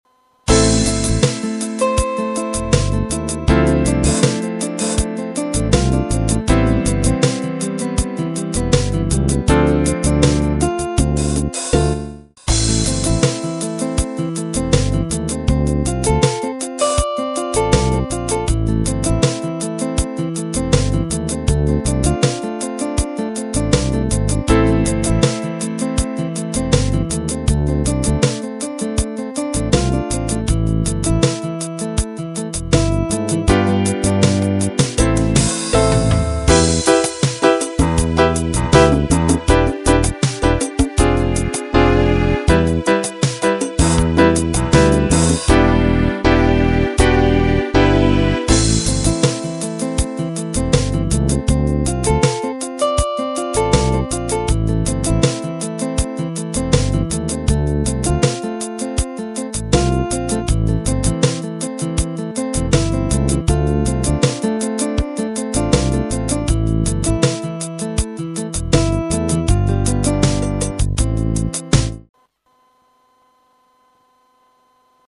Backing music